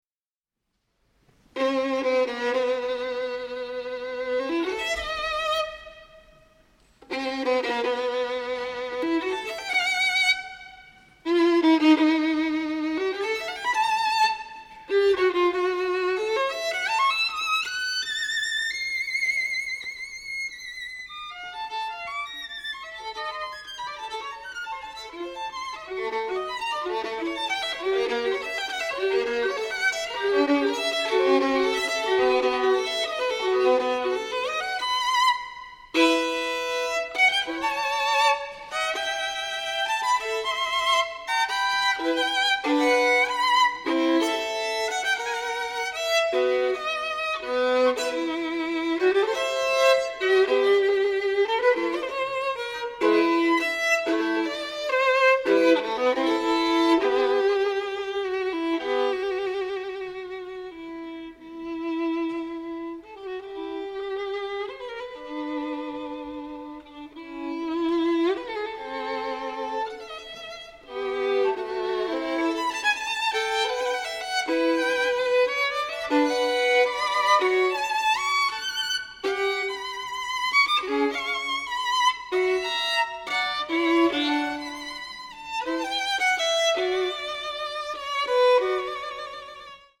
★ 多位國際級大師演繹經典名曲，以各自音樂造詣展現小提琴最千變萬化、無窮無盡的魅力！
Allemanda 4:44